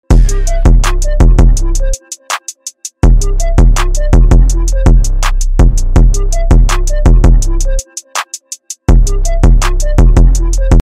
Play, download and share Snapchat Type Beat original sound button!!!!
snapchat-type-beat.mp3